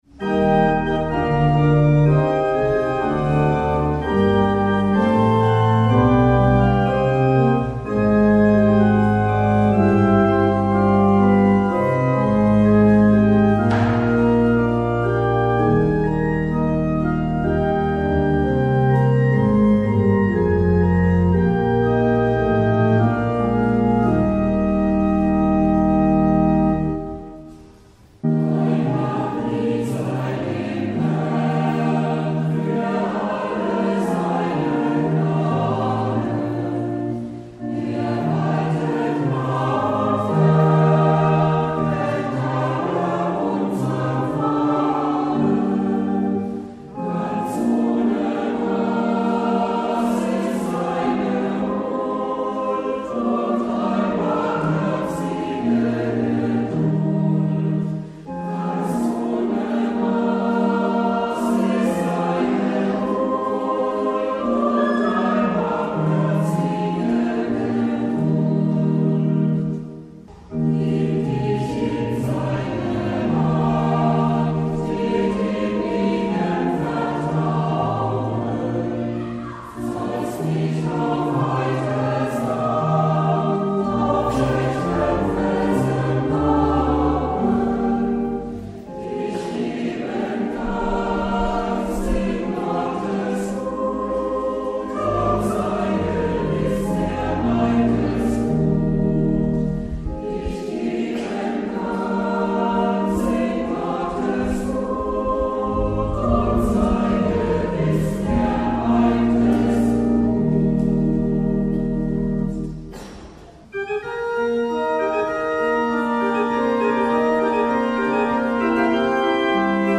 Hören Sie zwei Strophen dieses Liedes, gesungen vom Chor von St. Josef und eine festliche Orgelimprovisation, gespielt an der Orgel von St. Josef: